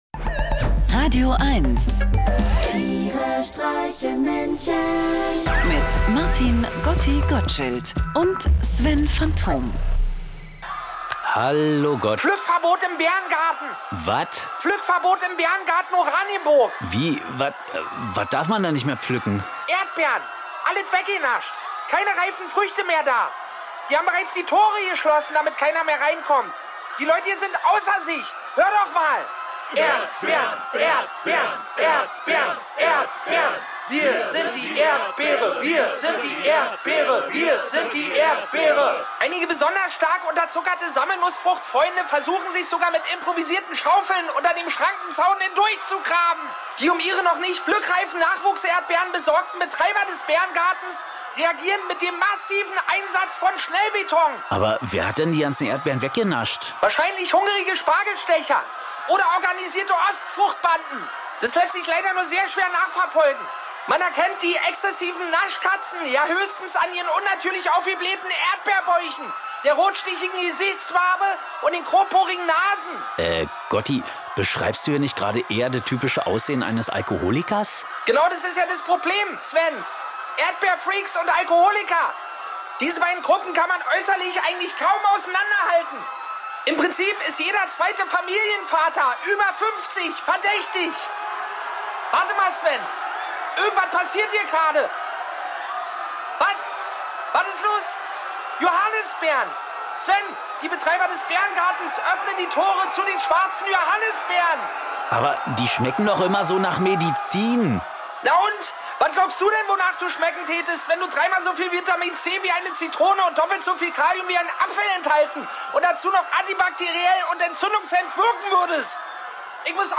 Einer liest, einer singt und dabei entstehen absurde, urkomische, aber auch melancholische Momente. Irgendwie mitten aus dem Leben und irgendwie bekloppt.